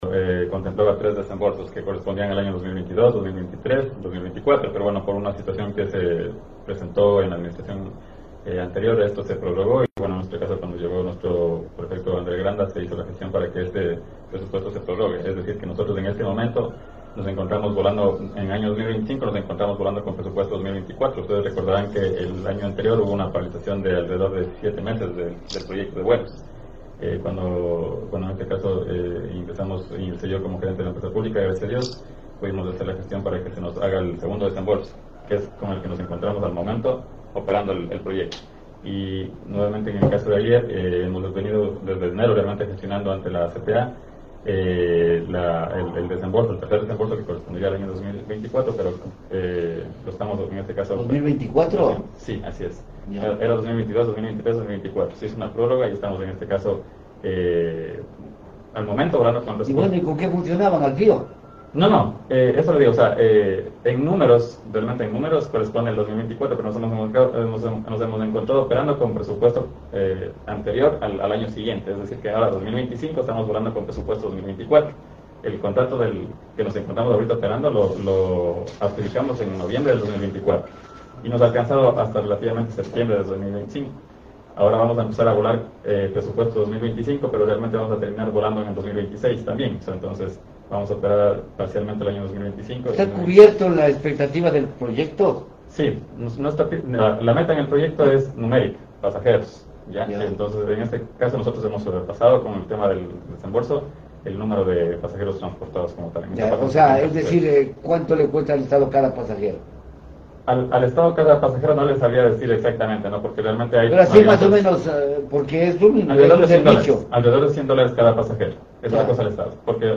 Entrevista: Ing.